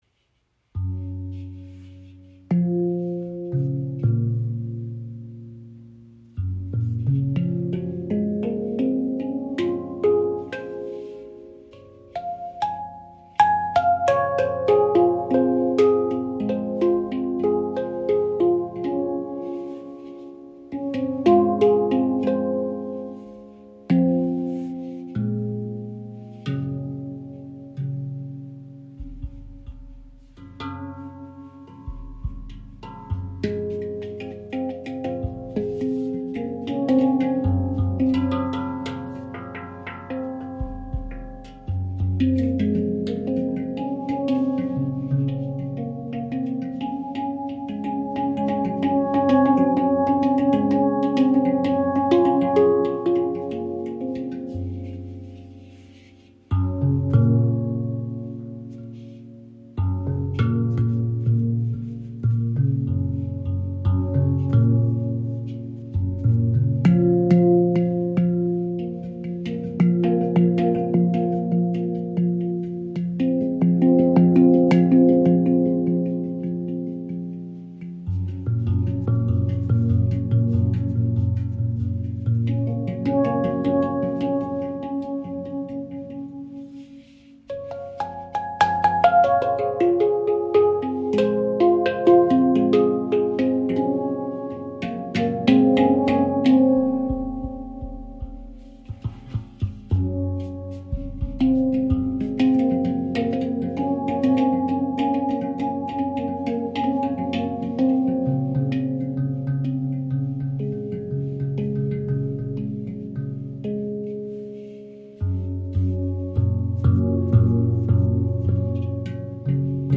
Handpan ShaktiPan | F#2 Nordlys | 15 Klangfelder – klar & schwebend
Klaren, mystischen Klang mit nordischem Charakter – ideal für meditative und atmosphärische Spielweisen.
• Icon Kühler, atmosphärischer Klang mit nordischer Tiefe
Die F#2 Nordlys Stimmung klingt klar, schimmernd und weit – ideal für kreative Improvisationen und klangvolle Entspannung.
Die F#2 Nordlys Stimmung umfasst die Töne: F# – (A B) – C# – D – E – F# – A – B – C# – D – E – F# – G# – A – B. Diese Skala wirkt klar, mystisch und vielschichtig – mit einem kühlen, nordischen Charakter.